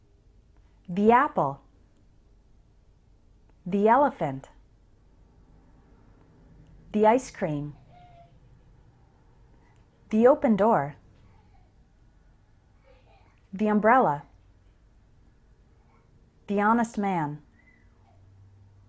Before a consonant sound we say THUH and before a vowel sound we say THEE.
How to pronounce THE + vowel sound